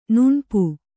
♪ nuṇpu